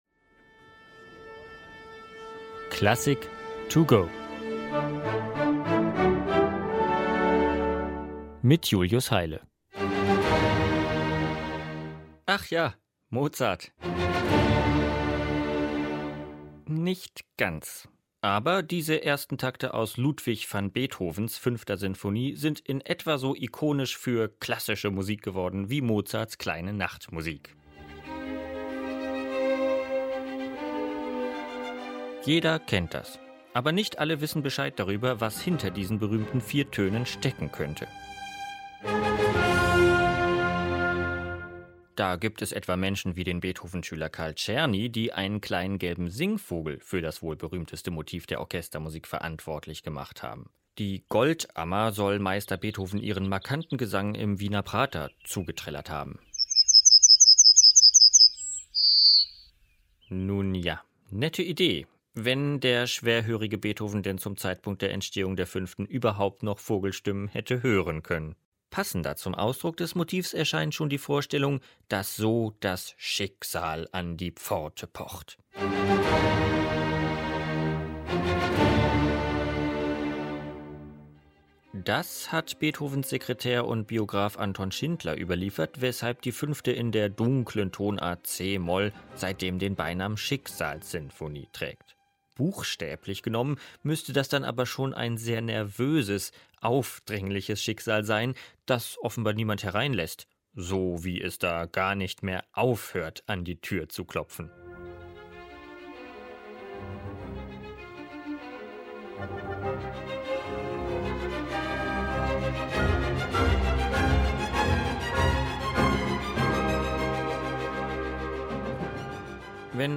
In der Werkeinführung für unterwegs